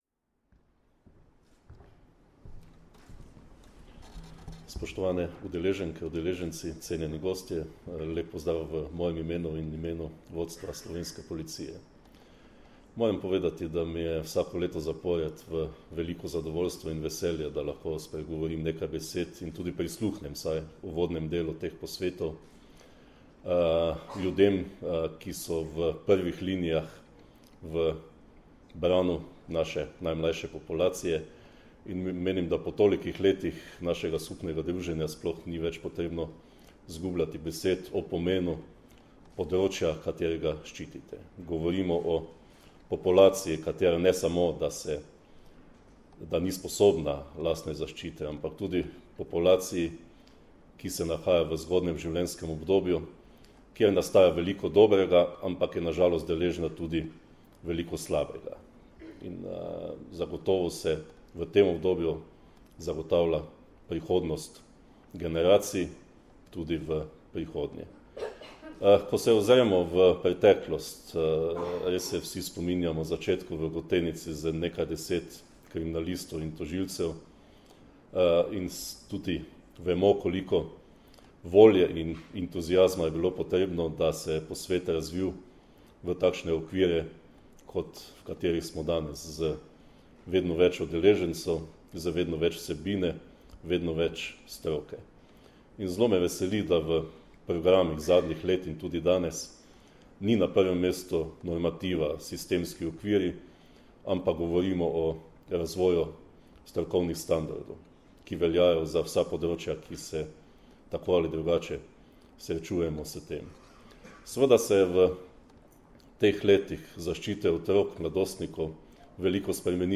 V Kongresnem centru Brdo, Predoslje, se je danes, 6. aprila 2017, pod naslovom V imenu otroka začel dvodnevni posvet na temo problematike poznavanja otroka za kvalitetnejšo obravnavo v postopkih, ki ga organizirata Policija in Društvo državnih tožilcev Slovenije v sodelovanju s Centrom za izobraževanje v pravosodju.
Zvočni posnetek nagovora Marjana Fanka (mp3)